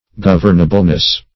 Search Result for " governableness" : The Collaborative International Dictionary of English v.0.48: Governableness \Gov"ern*a*ble*ness\, n. The quality of being governable; manageableness.
governableness.mp3